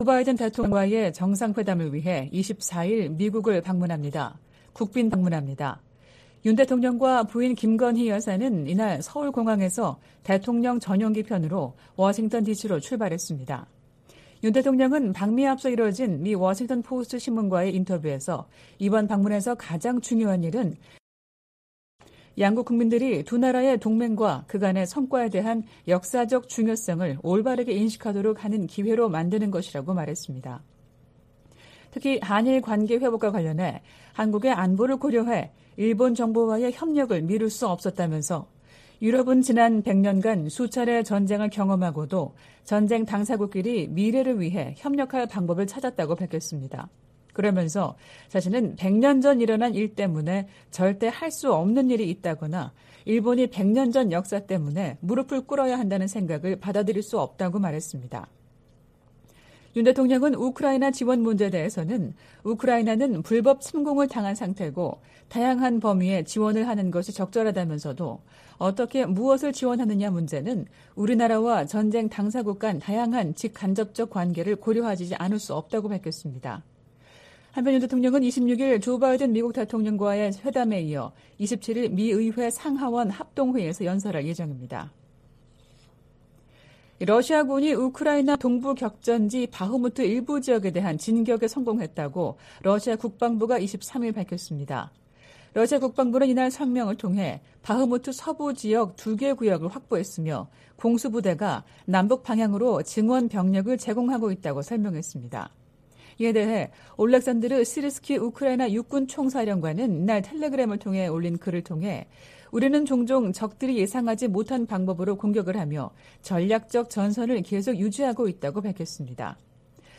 VOA 한국어 '출발 뉴스 쇼', 2023년 4월 25일 방송입니다. 백악관은 윤석열 한국 대통령의 국빈 방문이 미한 관계의 중요성을 증명하는 것이라고 강조했습니다.